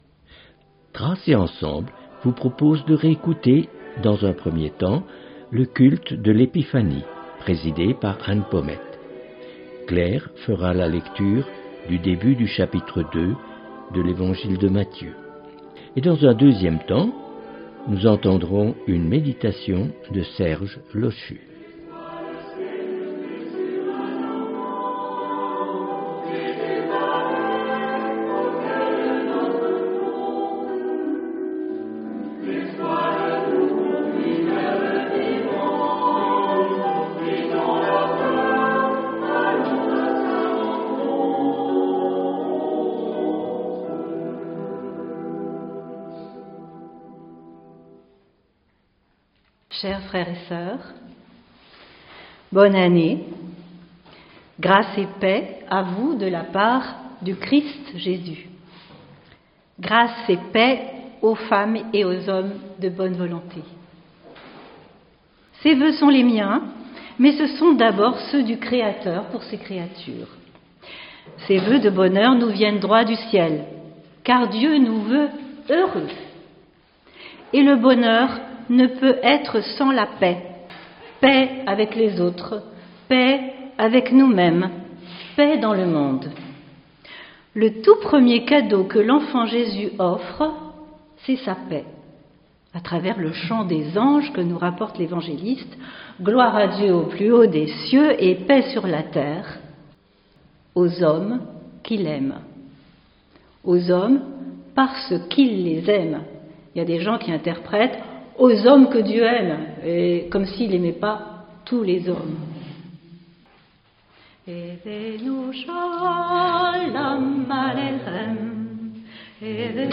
Lecture de Mt 2/1-12.
Culte